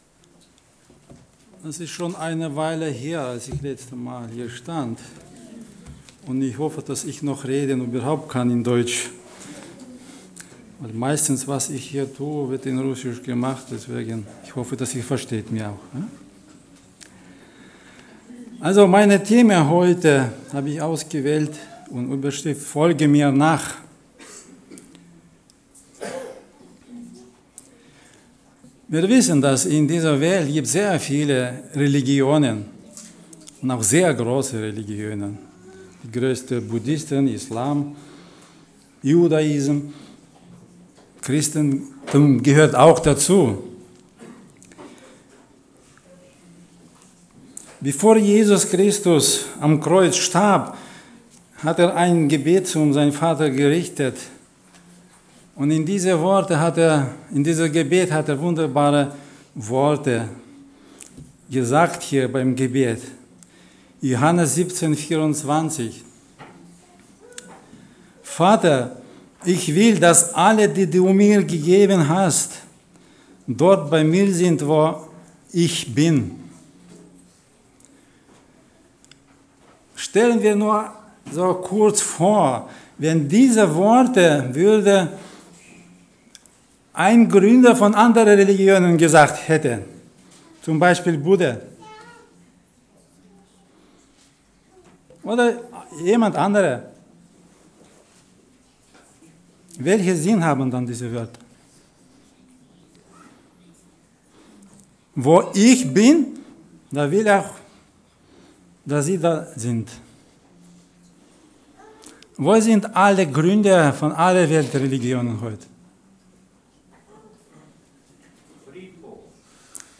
Predigten in mp3 - Blog